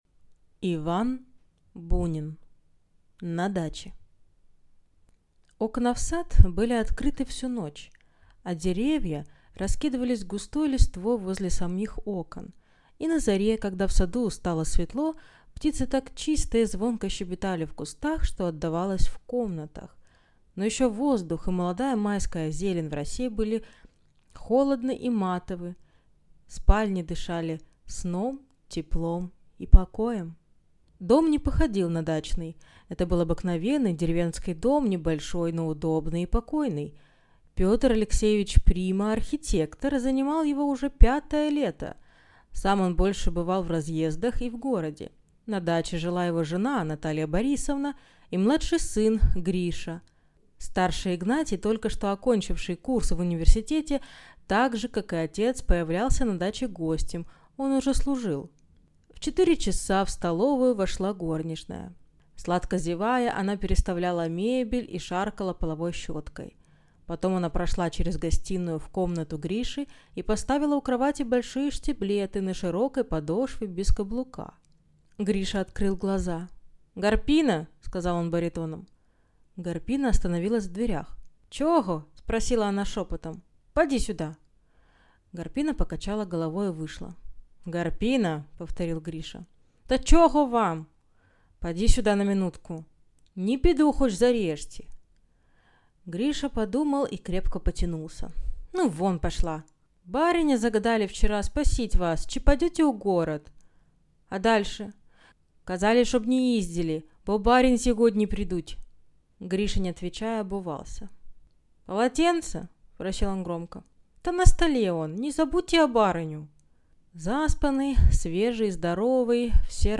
Аудиокнига На даче | Библиотека аудиокниг